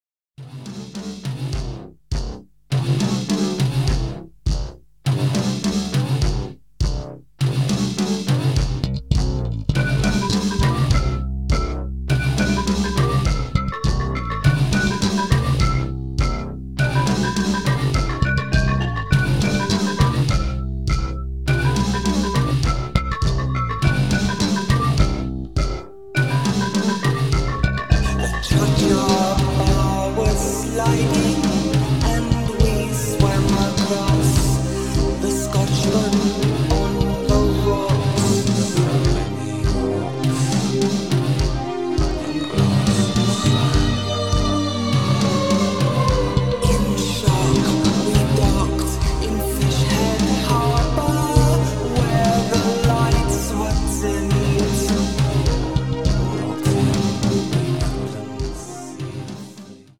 guest vocals
guest drums
synthesizers & British goth
psychedelia